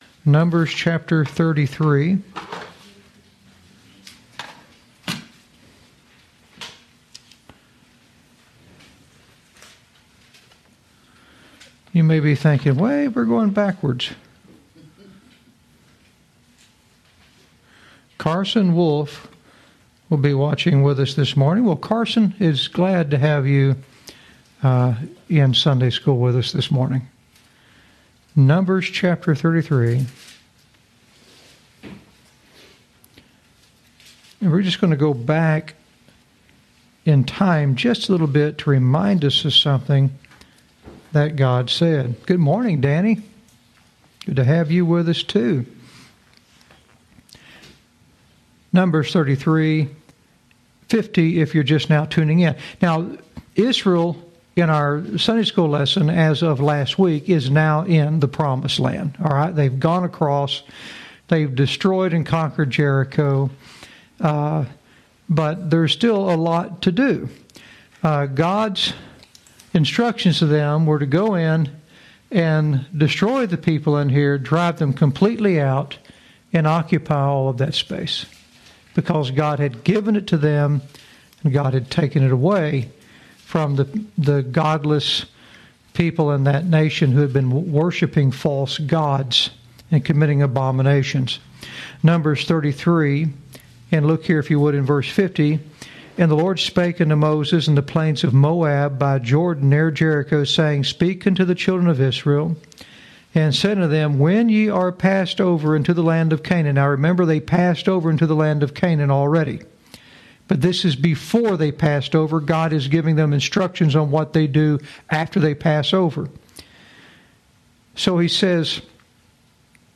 teaches verse by verse through the scriptures with the primary objective of communicating the Gospel of Christ, which is the power of God unto salvation, in a clear and simple light.